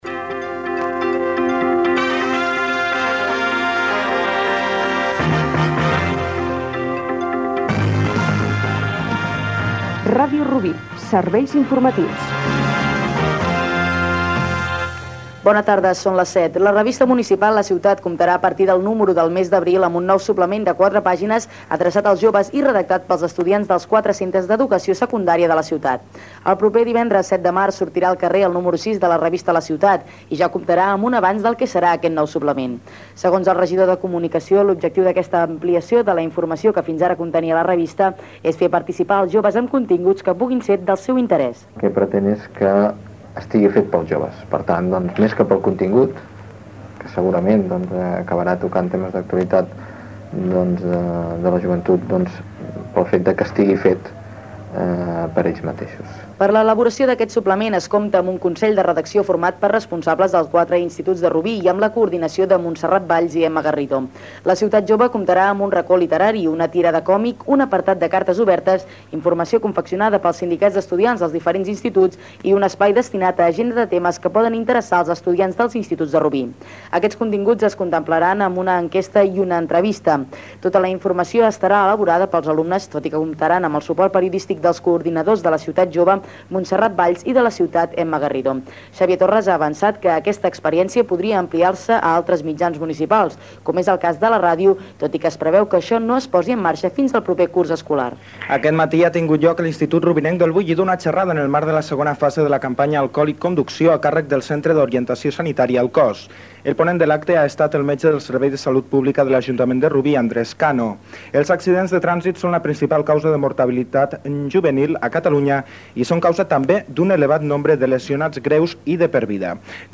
Careta, nou suplement de la revista "La ciutat" dedicat als joves, campanya alcohol i conducció, exposicions fetes per dues escoles rubinenques, comiat, careta de sortida.
Informatiu
Butlletí informatiu de les 19h.